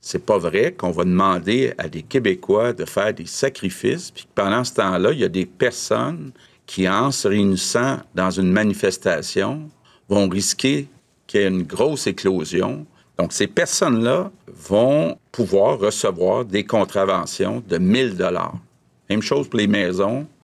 Même constat pour rappeler à l’ordre les manifestants qui défilent dans les rues sans masque, celui-ci étant désormais obligatoire lors de manifestations, rappelle le premier ministre François Legault: